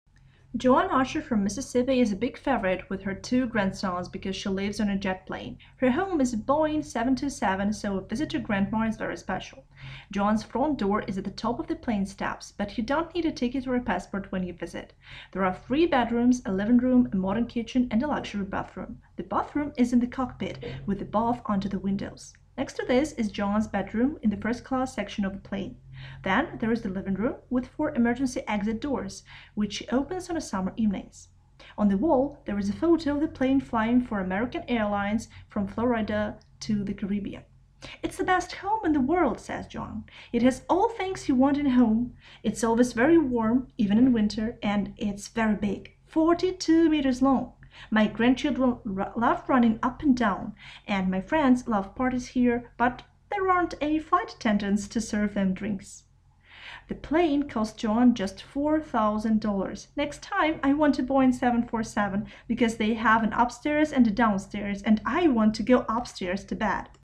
Вы услышите разговор в магазине подарков.